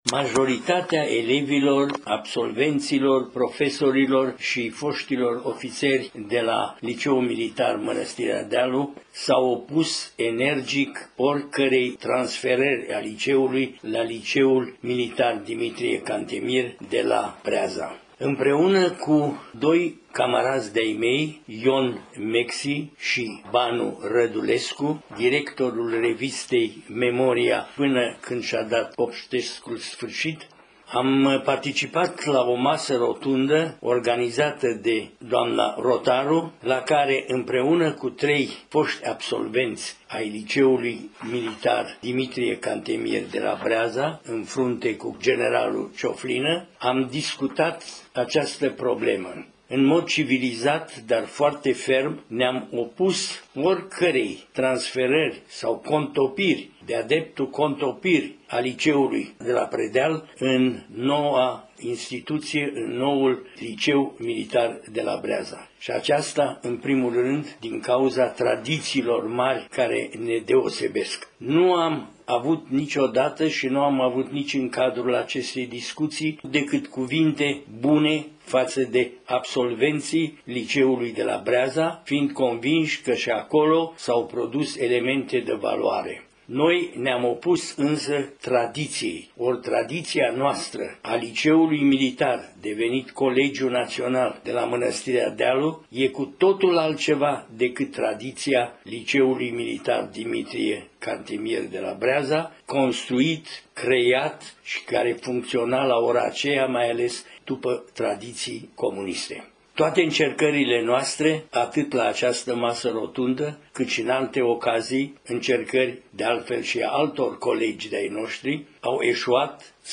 citind pentru tatăl său